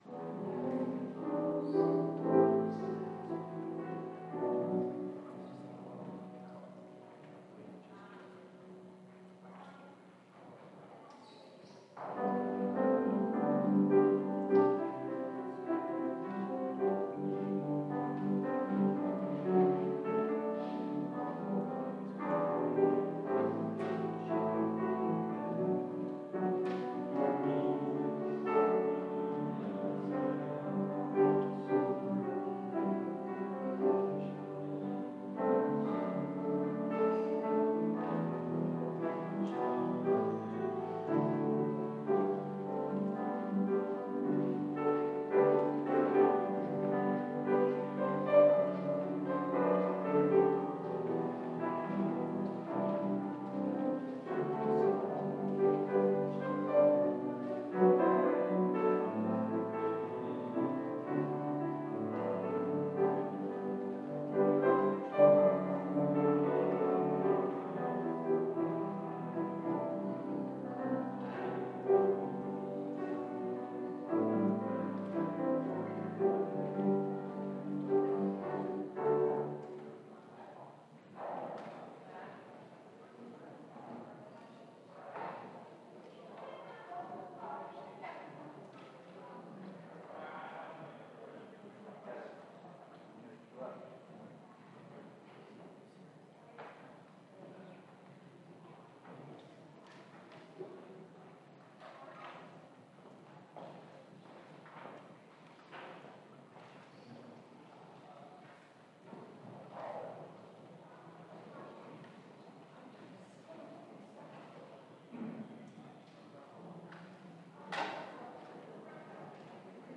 Sunday, 11 AM Mass - Thirtieth Sunday in Ordinary Time